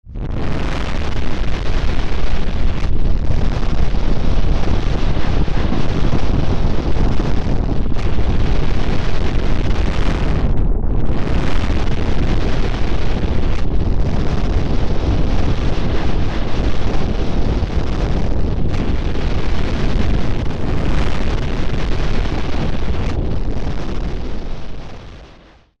Здесь собраны разные варианты: от далеких раскатов до близкого схода снежной массы.